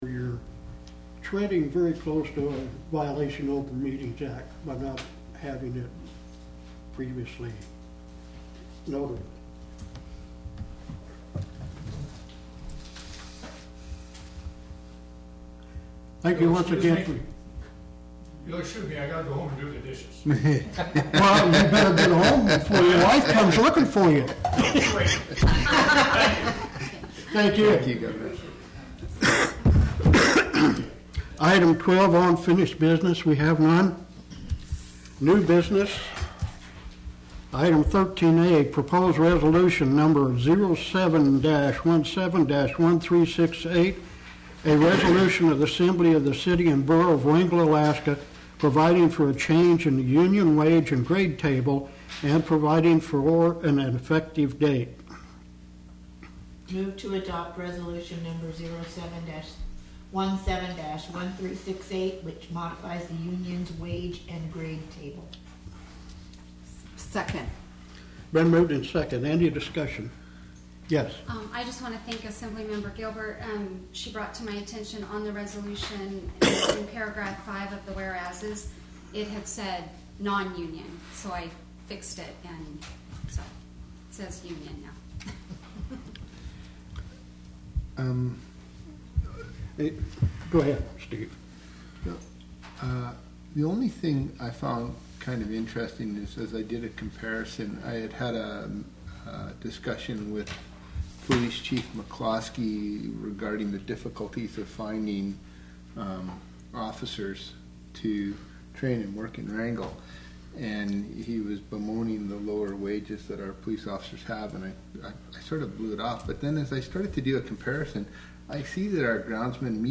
The Wrangell Borough Assembly met in a regular meeting at 7:00 pm on Tuesday, July 25th 2017 in Wrangell City Hall.
Tuesday, July 25, 2017 7:00 p.m. Location: Assembly Chambers, City Hall